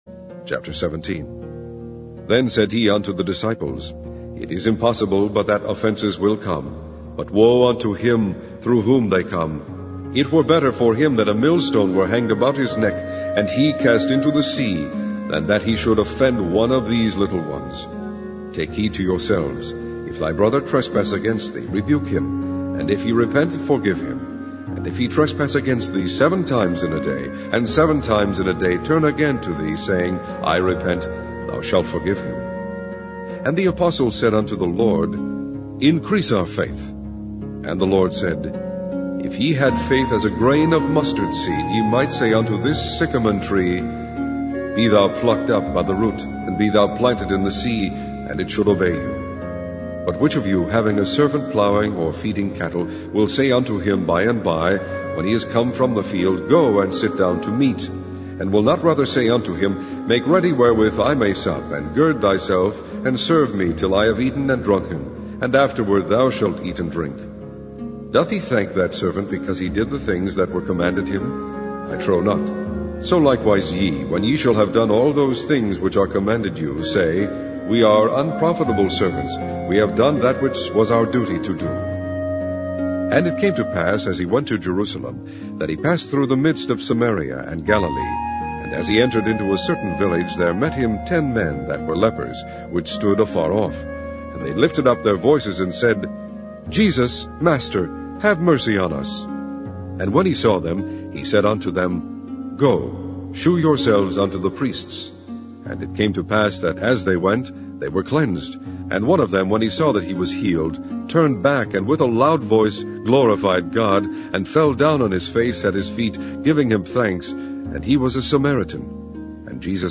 Online Audio Bible - King James Version - Luke